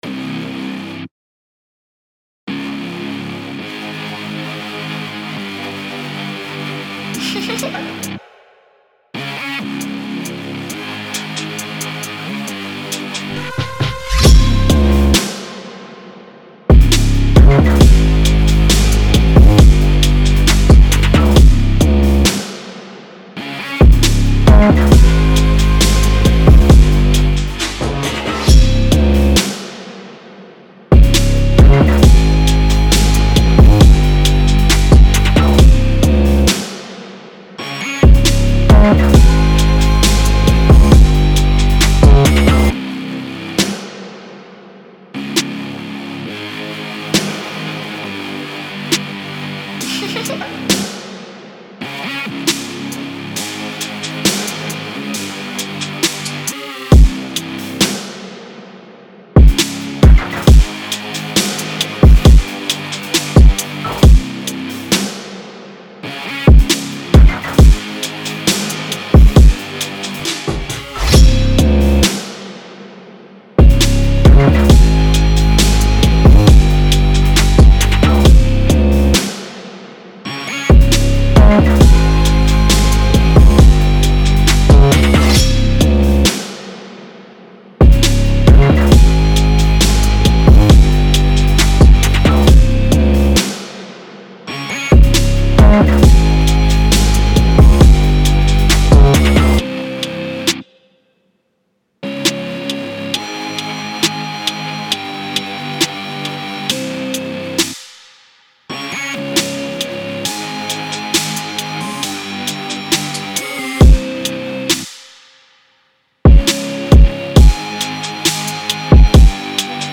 конечно доп мелодия не особо так вписывается из-за Ретро Калора который слетел и в итоге поставил пресет, а так нужно было подкрутить кое-что но не знаю)